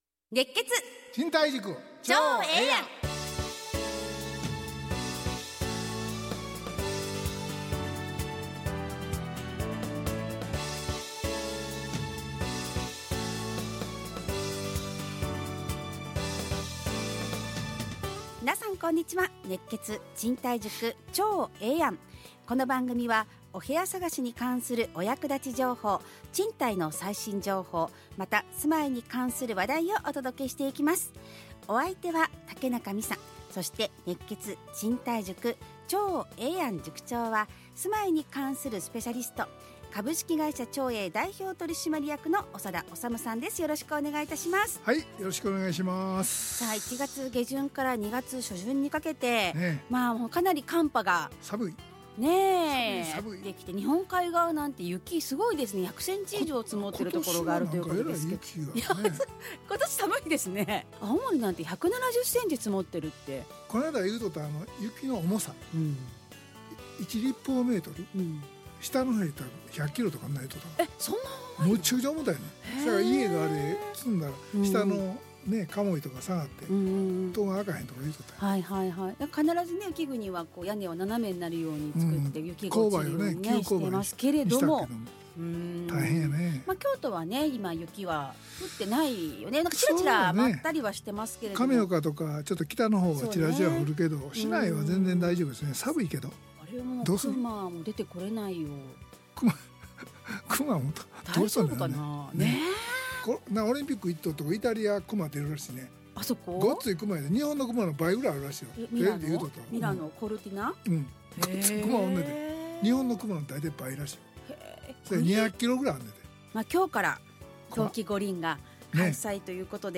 ラジオ放送 2026-02-06 熱血！